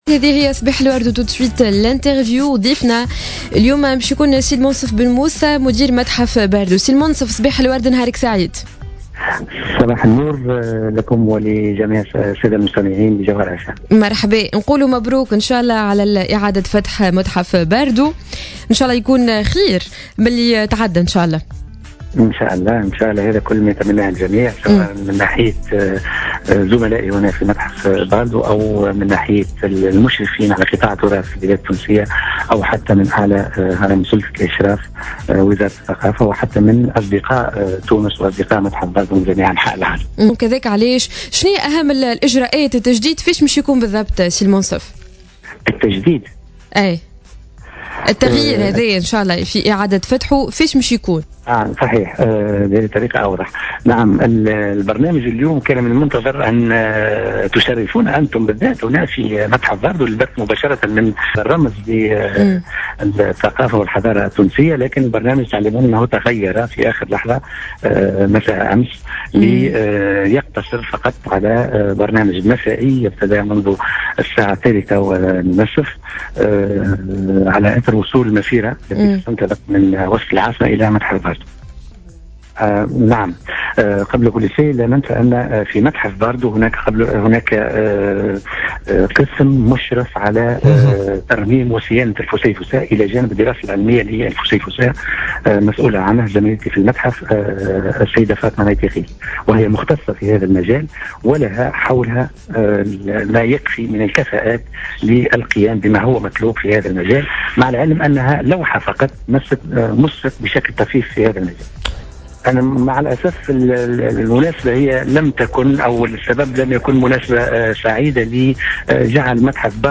في مداخلة له على جوهرة "اف ام" صباح اليوم